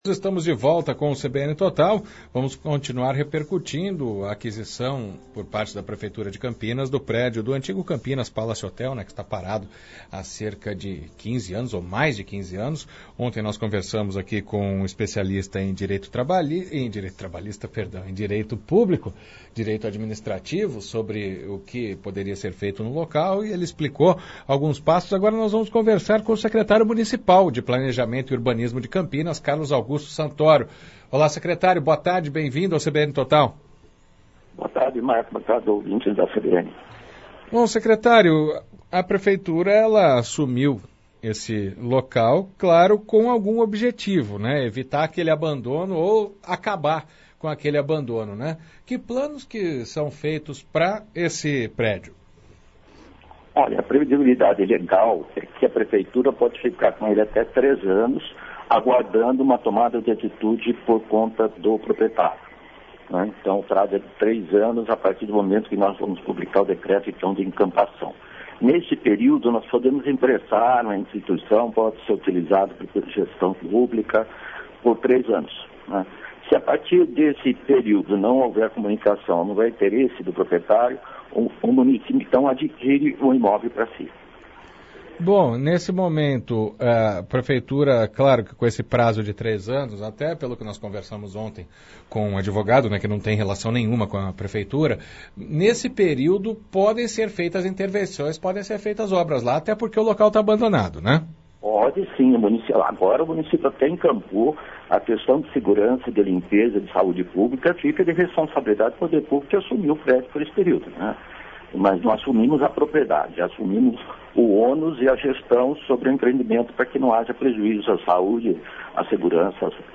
Secretário Municipal de Planejamento e Urbanismo, Carlos Augusto Santoro fala sobre os prédios que ela toma conta